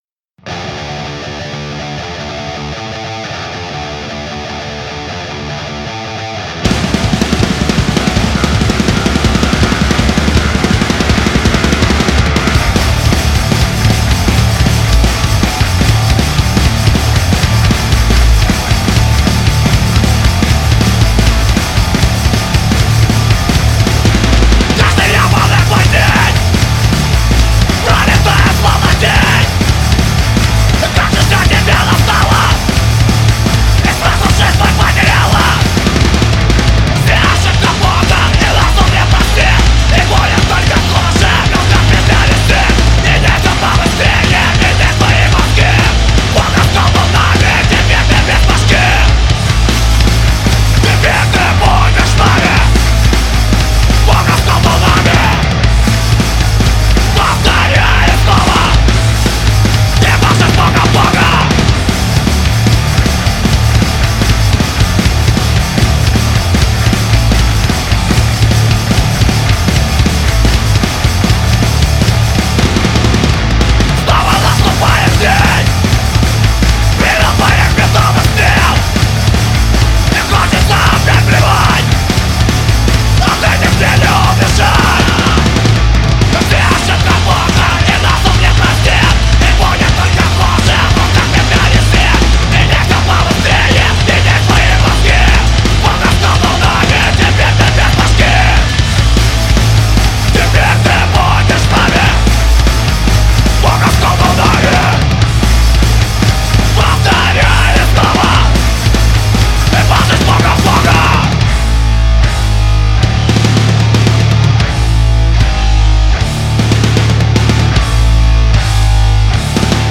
зацените пожалуйста трек! материал музыкантами одобрен, но интересно мнение продвинутых. записали 5 треков, но выкладываю один. писалали все одним дублем без метронома. барабаны, бас, гитара. потом прописали дабл трек гитарный и вокал